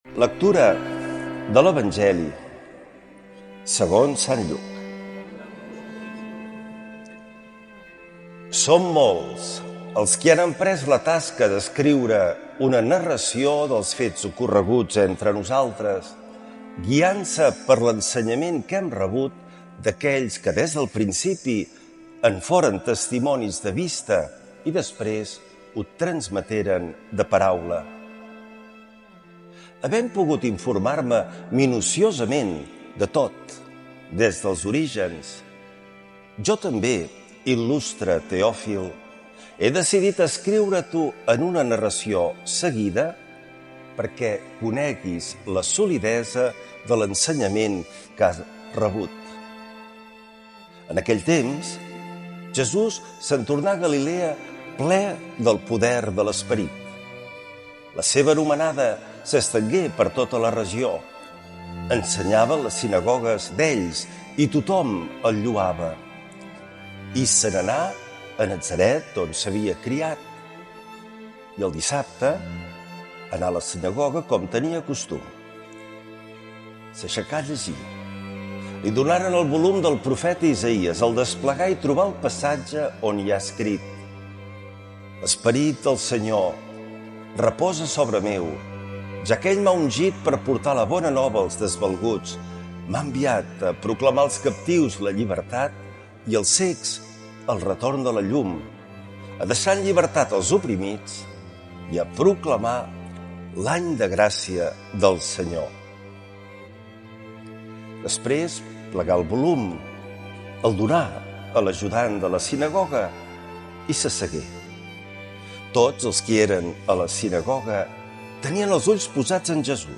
L’Evangeli i el comentari de diumenge 26 de gener del 2025.
Lectura de l’evangeli segons sant Lluc